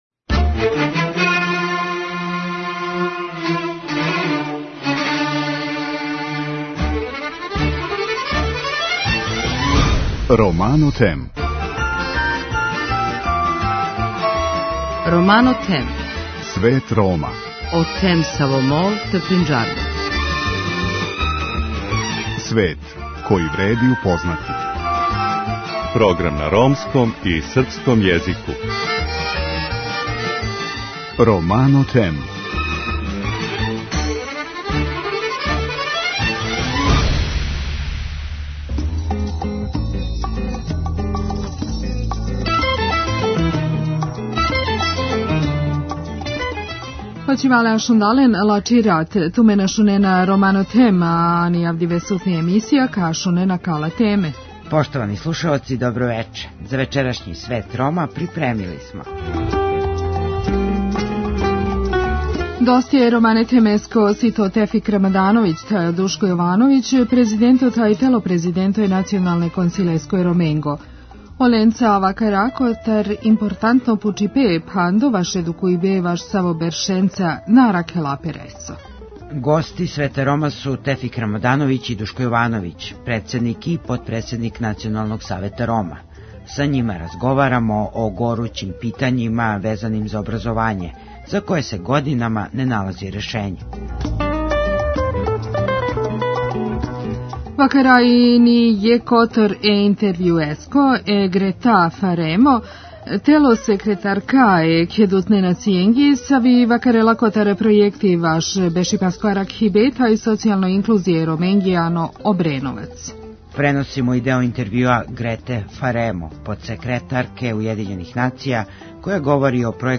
Преносимо и део интевјуа Грете Фаремо, подсекретарке Уједињених Нација, која говори о пројекту стамбеног збрињавања и социјалне инклузије Рома у Обреновцу. Центар за социјални рад Општине Рума поделио брикете за огрев породицама који живе у ромском насељу Вашариште.